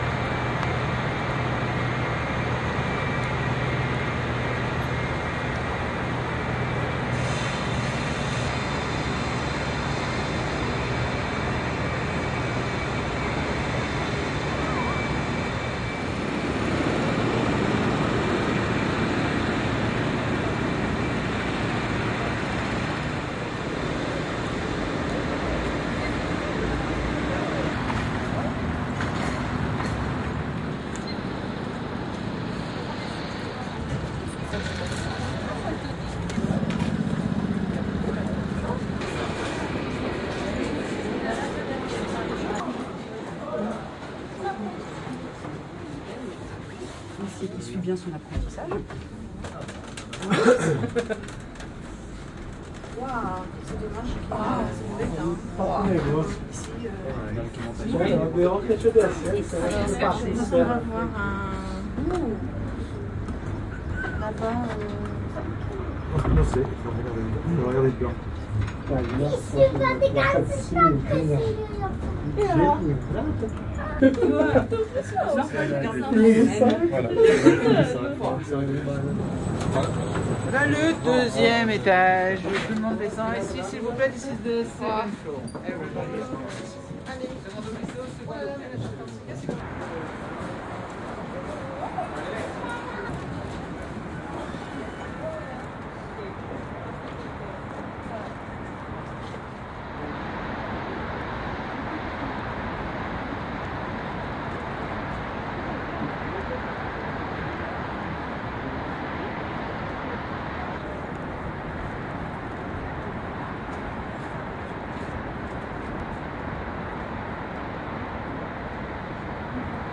大雨 一些人 2
描述：柏林克鲁兹堡的夏雨。你可以听到背景中的一些人和一些城市噪音。
声道立体声